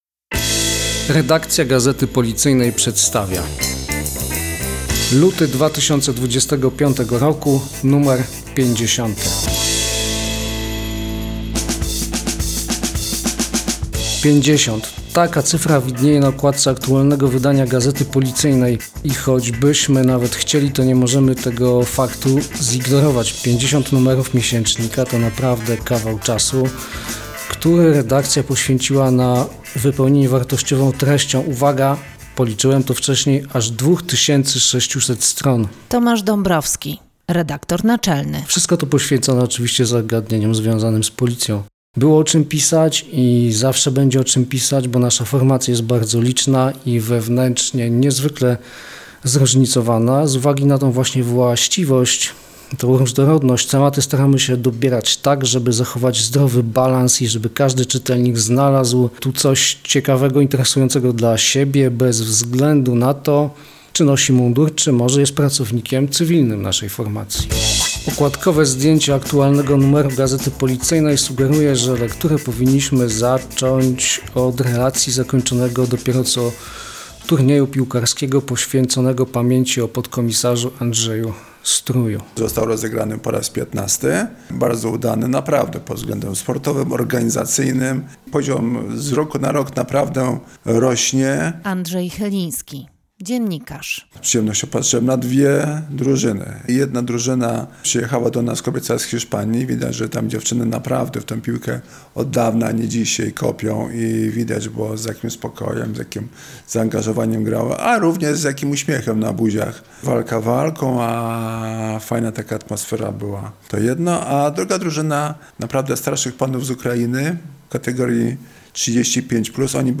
Siłą rzeczy jest to więc także jubileuszowy odcinek gazetowego podcastu. Dziennikarze naszej redakcji opowiadają w nim o najważniejszych artykułach, jakie dla Was przygotowali tym razem.